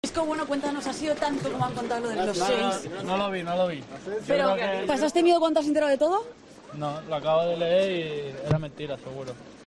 El jugador del Real Madrid habló en exclusiva con El Golazo de Gol a su llegada a Madrid sobre el capítulo del cuchillo después del partido frente a Israel: "No lo vi. Lo acabo de leer será mentira seguro".